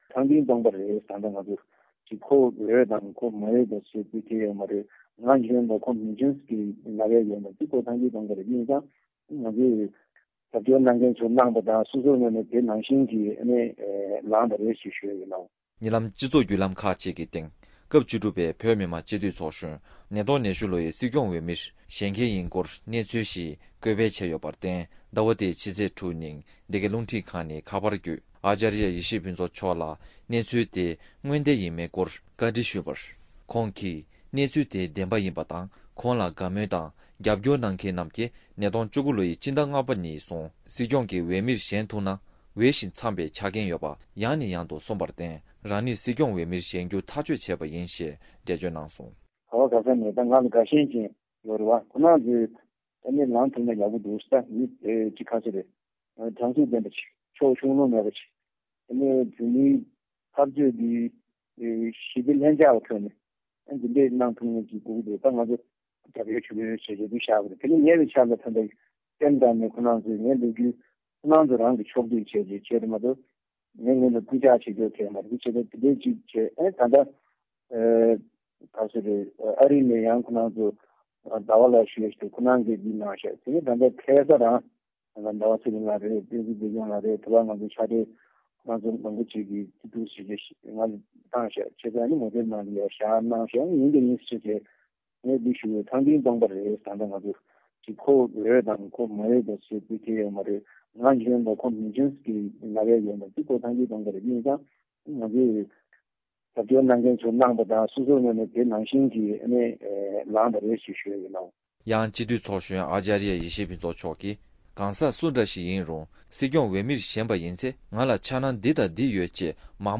སྒྲ་ལྡན་གསར་འགྱུར། སྒྲ་ཕབ་ལེན།
ཉེ་ལམ་སྤྱི་ཚོགས་བརྒྱུད་ལམ་ཁག་ཅིག་གི་སྟེང་། སྐབས་བཅུ་དྲུག་པའི་བོད་མི་མང་སྤྱི་འཐུས་ཚོགས་གཞོན་ཨཱཅརྱ་ཡེ་ཤེས་ཕུན་ཚོགས་ལགས་༢༠༢༡་ལོའི་སྲིད་སྐྱོང་འོས་མིར་བཞེངས་མཁན་ཡིན་སྐོར་གྱི་གནས་ཚུལ་ཞིག་བཀོད་སྤེལ་བྱས་ཡོད་པར་བརྟེན། ཟླ་བ་འདིའི་ཕྱི་ཚེས་༦་ཉིན་འདི་གའི་རླུང་འཕྲིན་ཁང་གིས་ཁ་པར་བརྒྱུད་ཨཱཅརྱ་ཡེ་ཤེས་ཕུན་ཚོགས་མཆོག་ལ་བཅར་འདྲི་བྱས་སྐབས། ཁོང་གིས་གནས་ཚུལ་དེ་བདེན་པ་རེད། ཁོང་མ་འོངས་༢༠༢༡་ལོའི་བོད་མིའི་སྒྲིག་འཛུགས་ཀྱི་སྲིད་སྐྱོང་འོས་མིར་ལངས་རྒྱུ་ཐག་གཅོད་བྱས་པ་ཡིན་ཞེས་འགྲེལ་བརྗོད་གནང་སོང་།